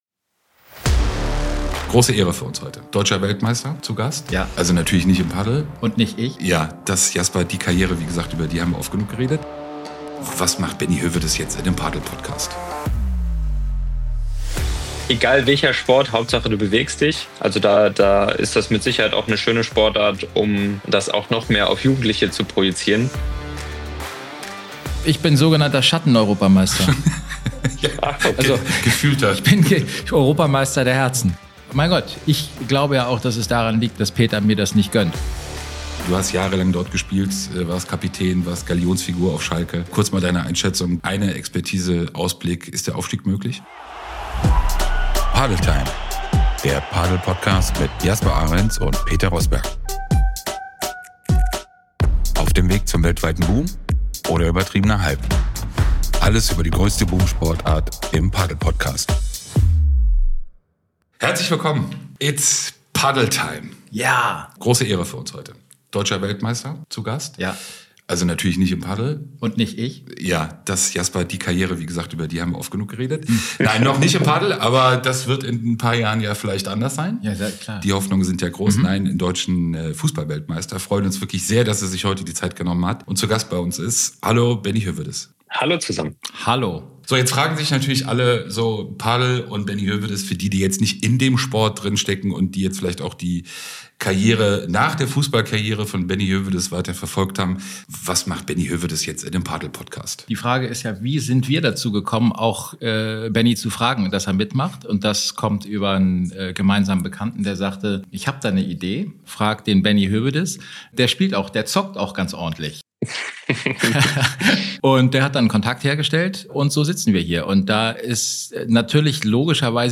sprechen in der aktuellen Folge mit Benedikt Höwedes